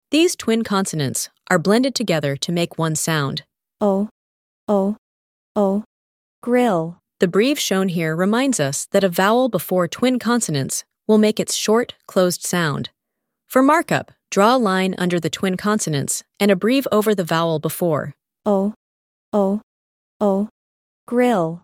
LL-grill-lesson-Ai-a.mp3